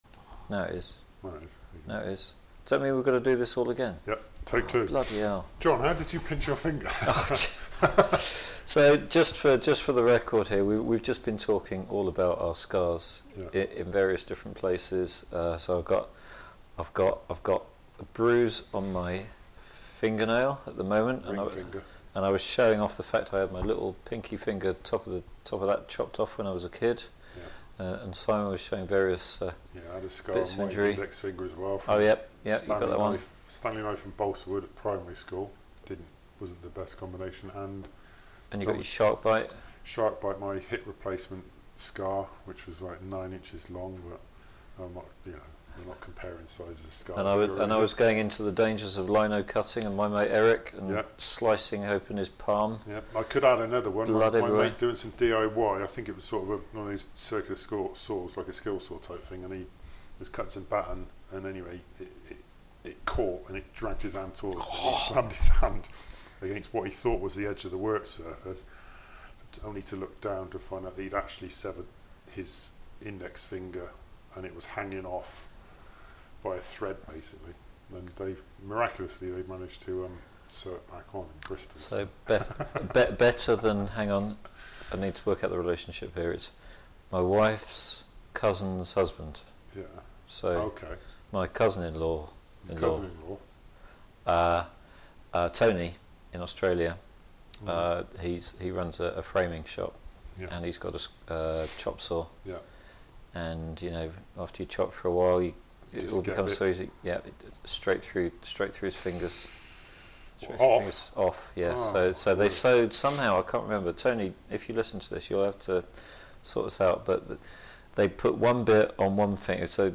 Unscripted, unfiltered, and unquestionably something.